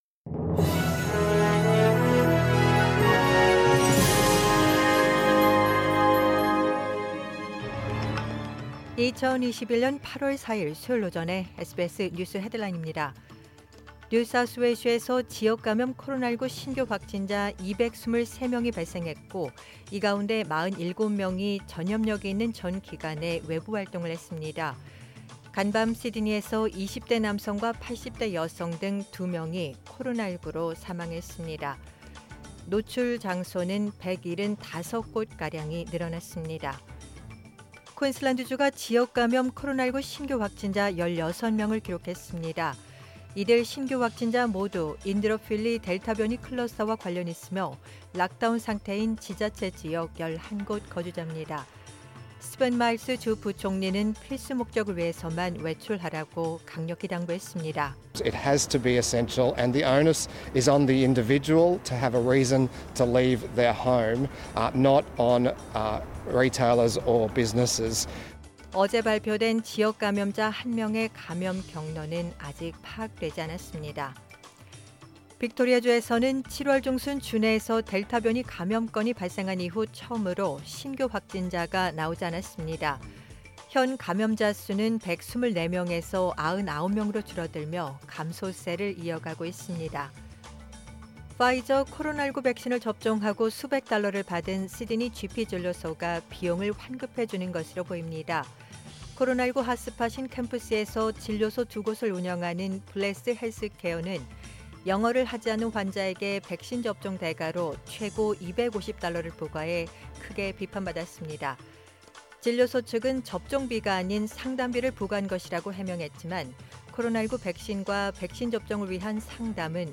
2021년 8월 4일 수요일 오전의 SBS 뉴스 헤드라인입니다.